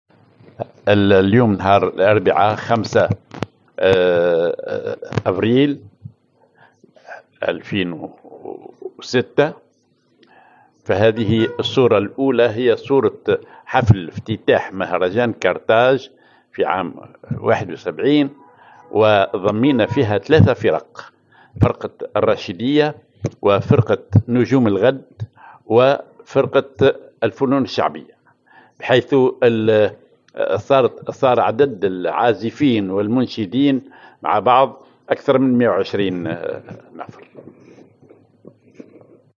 en Opening ceremony of the Carthage Festival.
en A group made up of three musical ensembles: Al-Rachidia, the Najm El Ghad (Stars of Tomorrow) troupe, and the Folk Arts troupe — totaling around 120 members, including instrumentalists and vocalists.